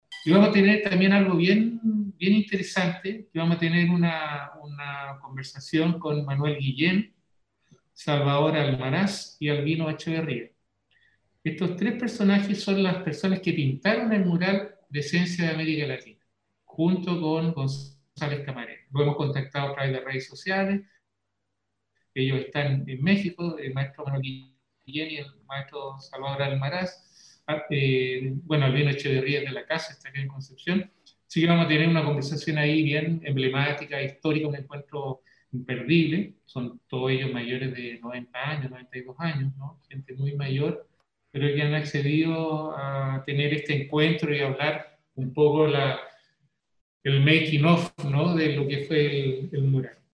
entrevista completa